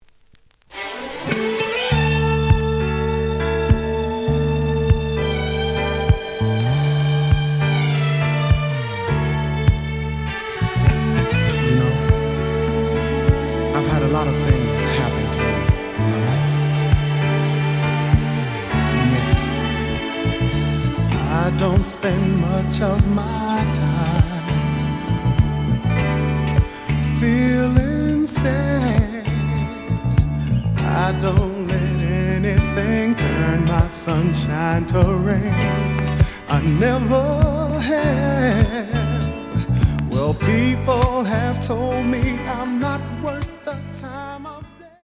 The audio sample is recorded from the actual item.
●Genre: Soul, 80's / 90's Soul